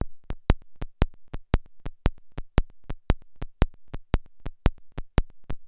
57-OSC.CLI-L.wav